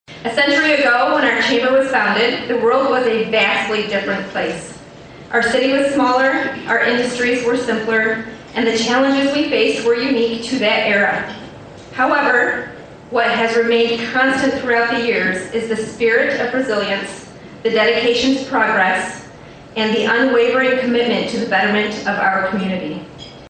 The Sturgis Area Chamber of Commerce has reached the 100 year mark and celebrated the milestone Thursday night during their Annual Dinner.